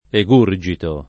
egurgito [ e g2 r J ito ]